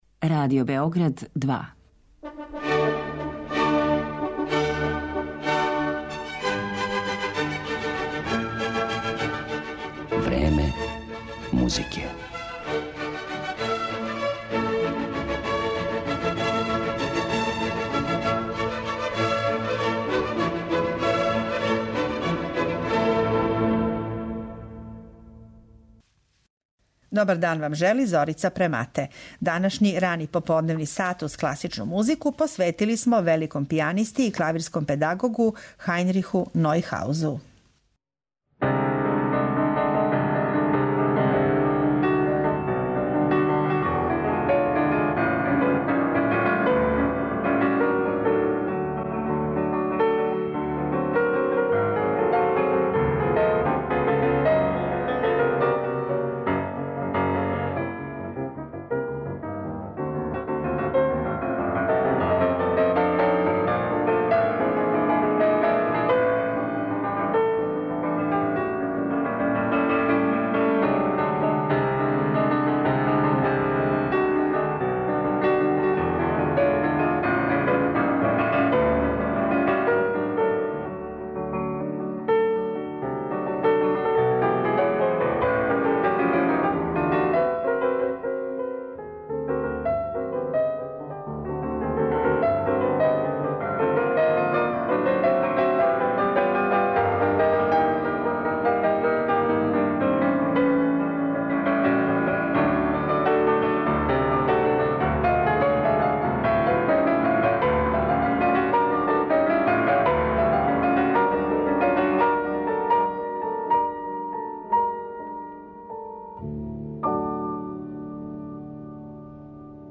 У емисији ћете слушати архивске снимке које је Нојхауз остварио у својим позним годинама, средином прошлог века, а са делима Скрјабина, Моцарта и Дебисија.